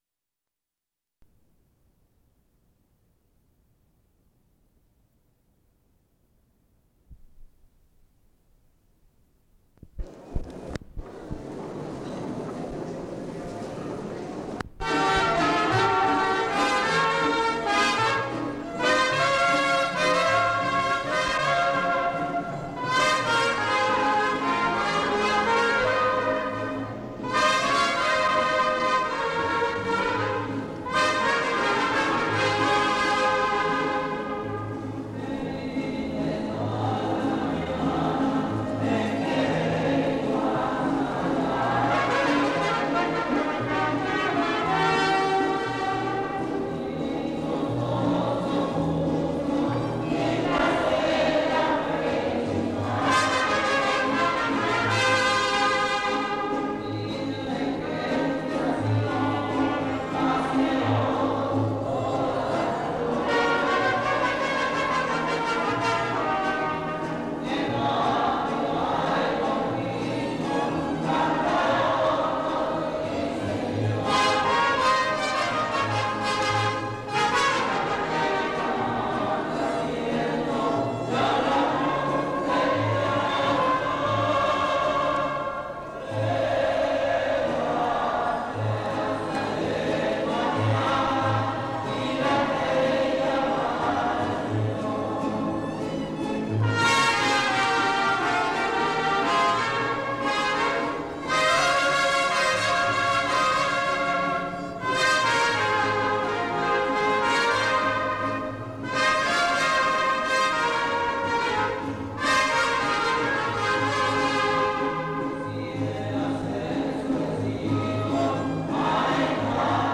Fiesta del Señor Santiago